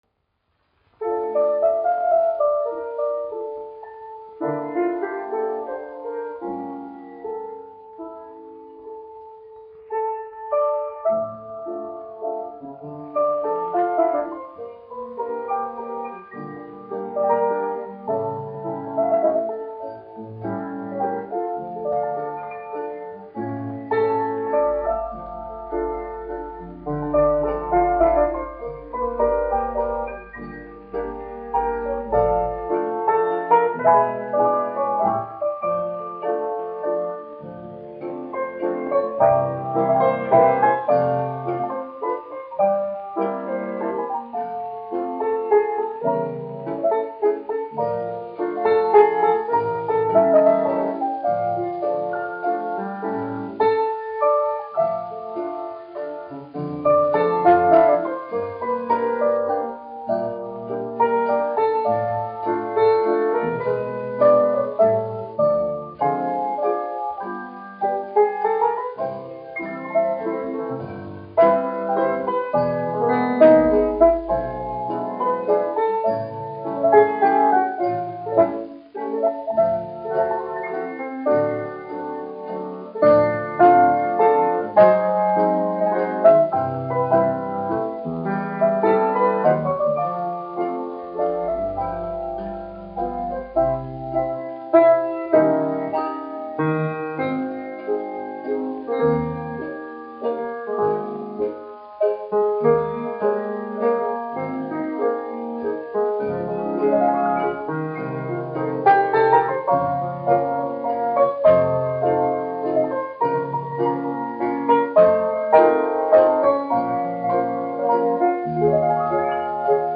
1 skpl. : analogs, 78 apgr/min, mono ; 25 cm
Džezs
Populārā instrumentālā mūzika
Skaņuplate